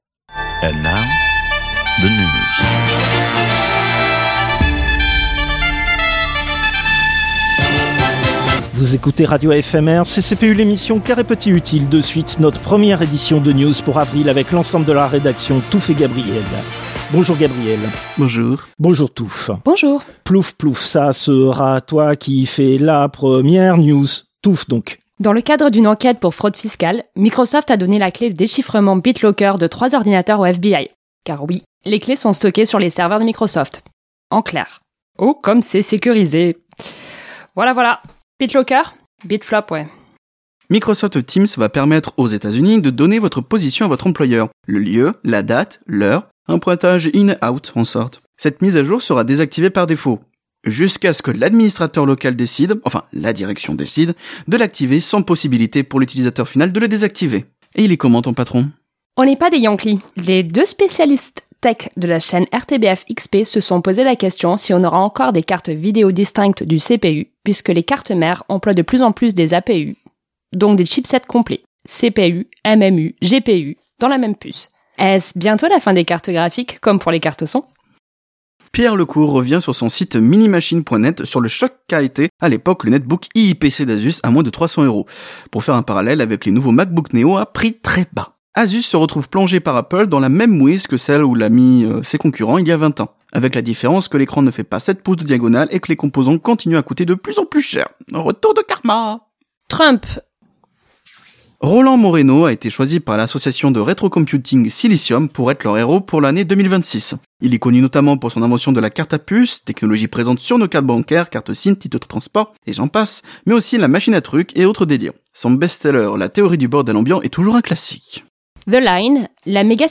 News (avril 2026)